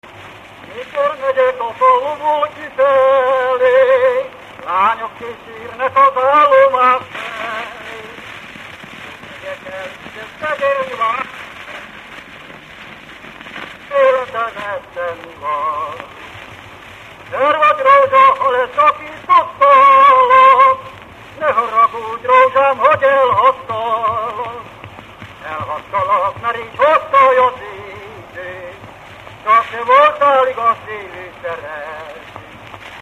Erdély - Kolozs vm. - Nyárszó
Stílus: 4. Sirató stílusú dallamok
Kadencia: 6 (5) b3 1